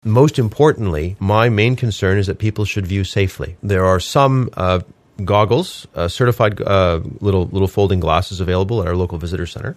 Beddows welcomes visitors and residents to enjoy the day and has this message to pass on.